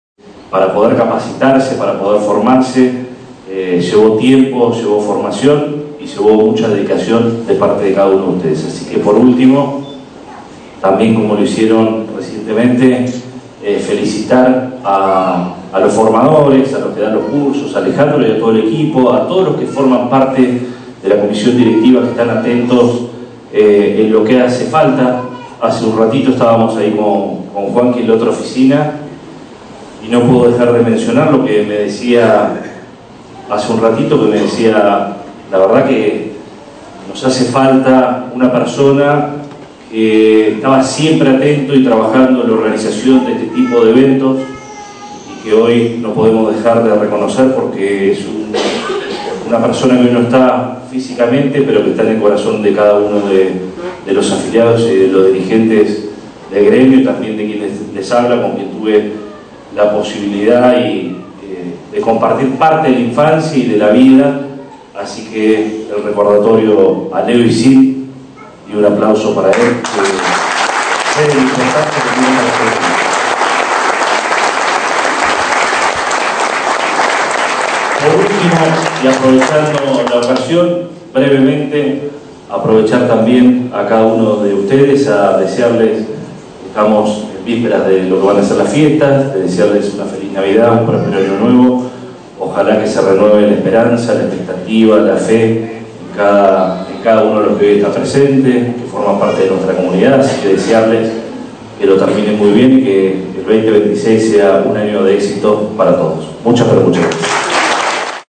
Ante un importante marco de público, el intendente Rojas brindó unas palabras de felicitación a quienes completaron su trayecto formativo.